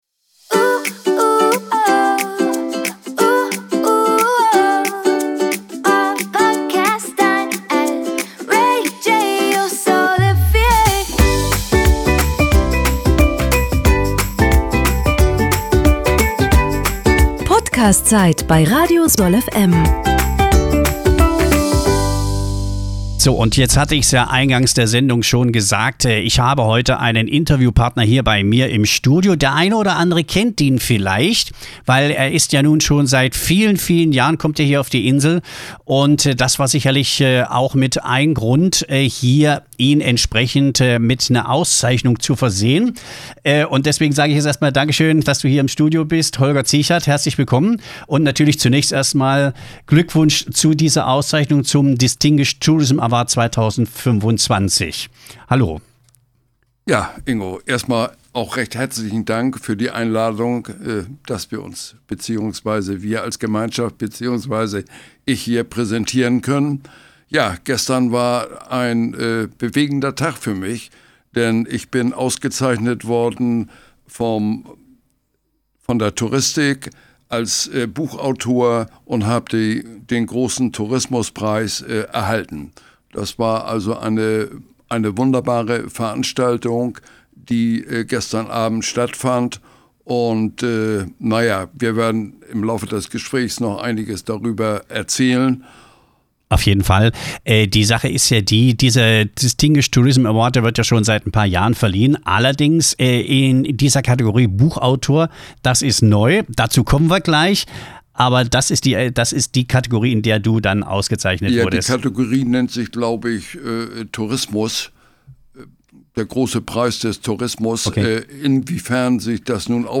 🌴 Hier das komplette Interview als P0dcast zum Anhören: Von Brötchen holen zum Award-Gewinner https